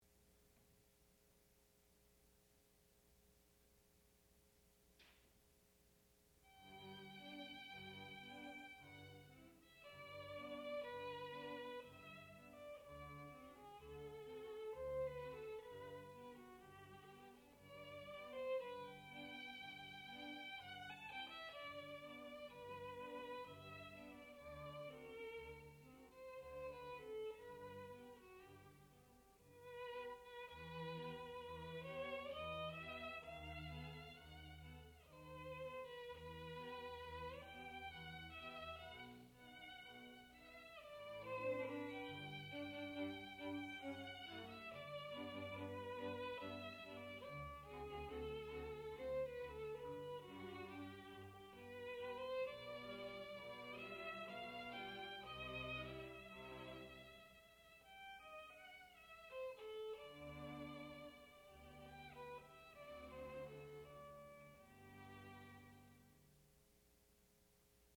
sound recording-musical
classical music
violin